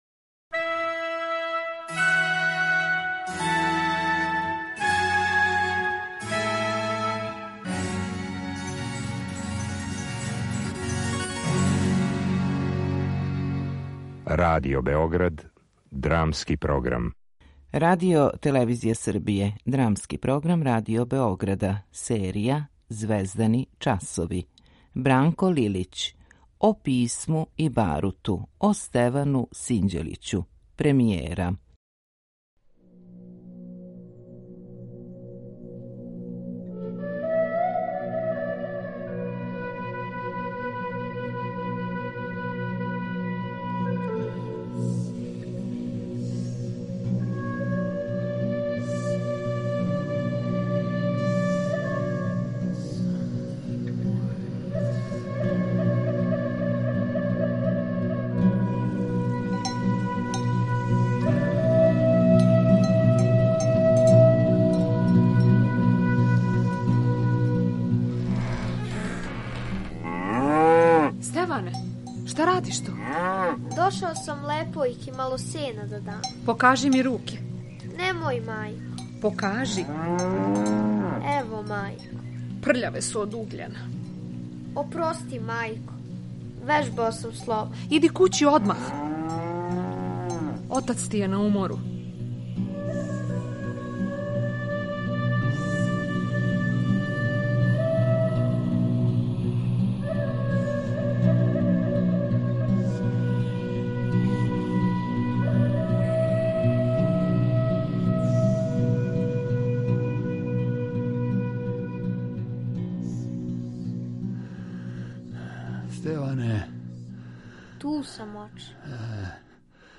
Биографска драма о српском војсковођи Стевану Синђелићу (1770-1809).